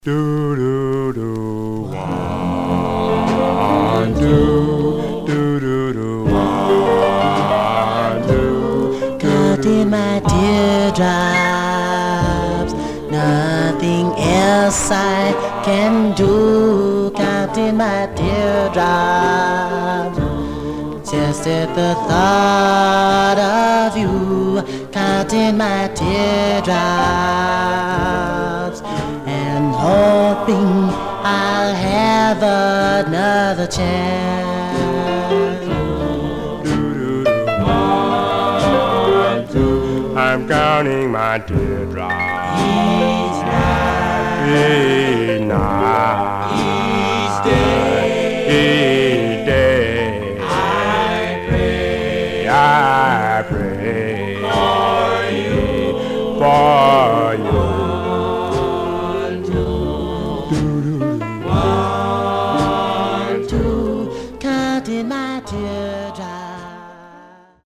Stereo/mono Mono
Male Black Group